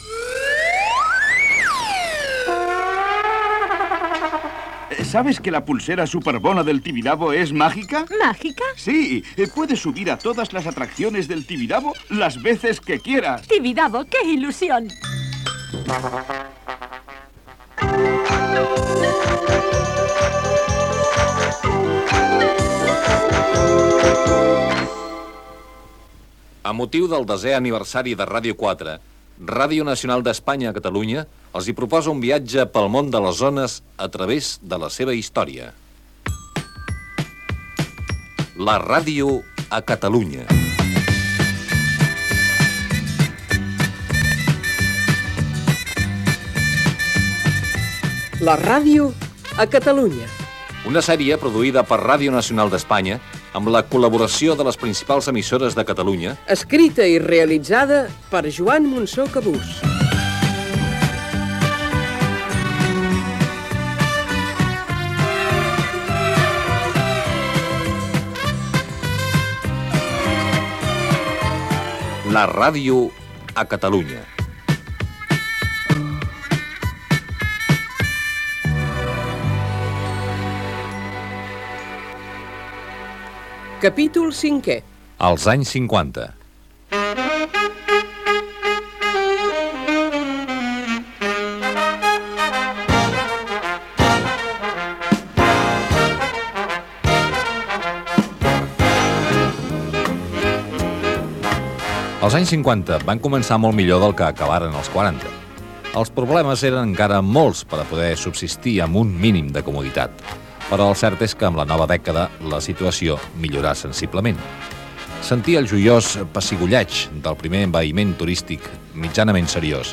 Publicitat, sintonia de la ràdio, careta
Gènere radiofònic Divulgació